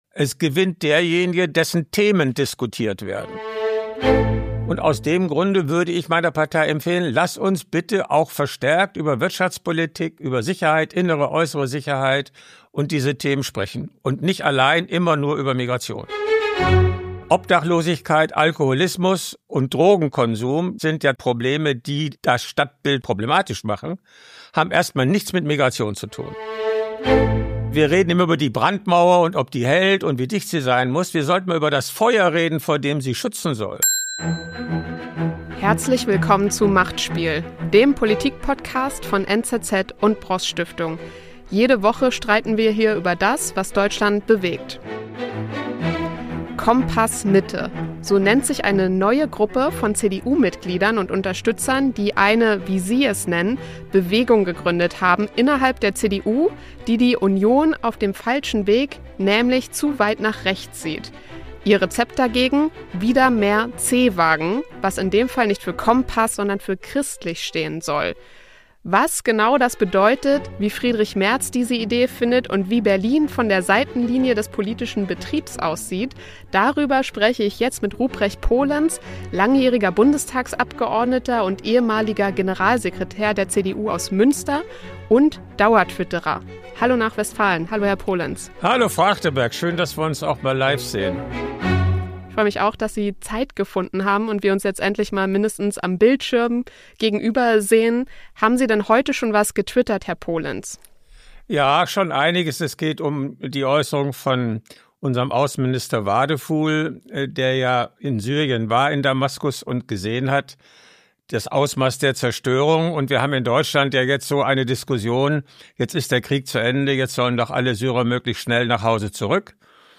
Ein Gespräch über Sprache, Verantwortung und die Frage, wie die CDU wieder Volkspartei der Mitte werden kann.